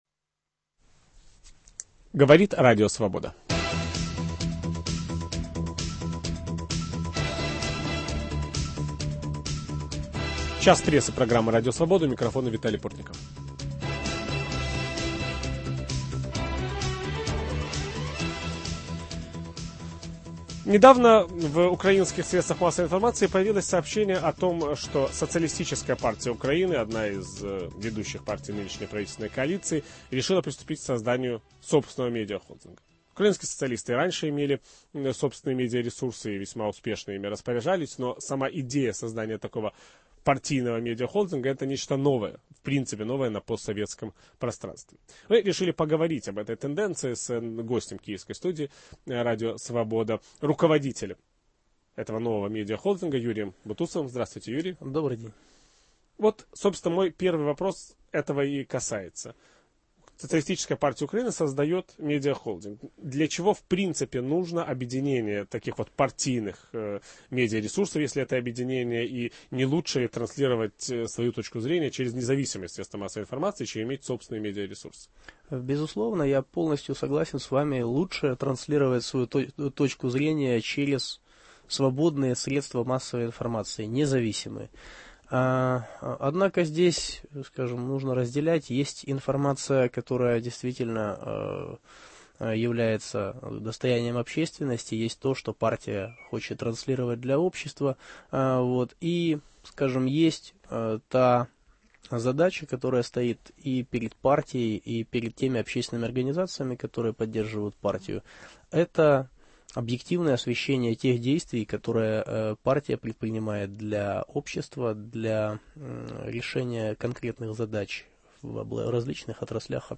Украинские социалисты создают свой медиа-холдинг. В киевской студии Радио Свобода